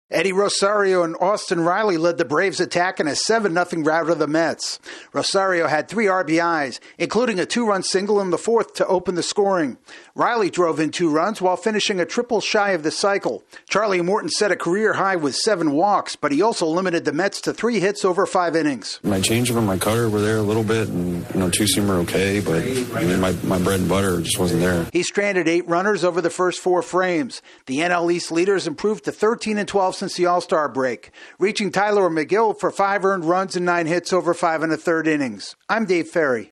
The Braves have an easy time winning their series opener against the Mets. AP correspondent